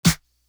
Movie Little Clap.wav